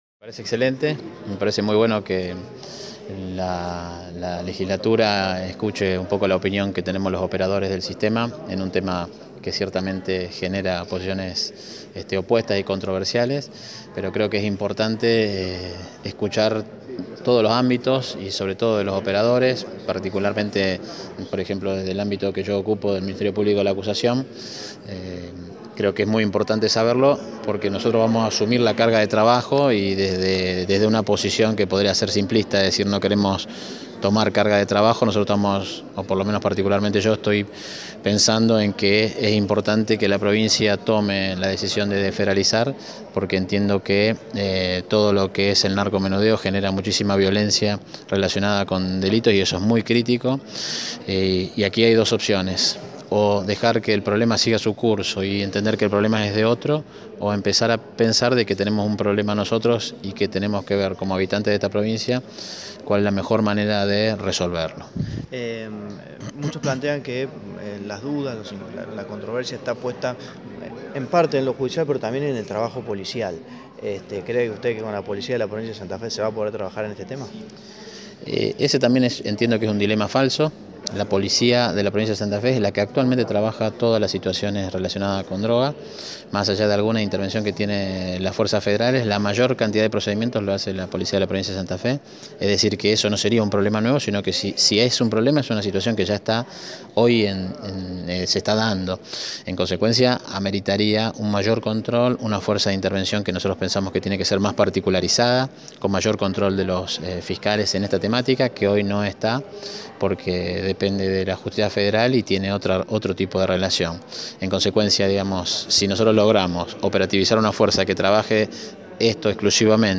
El Fiscal General, Jorge Baclini, habló hoy en la charla-debate que se dio en la Legislatura Provincial por el Narcomenudeo.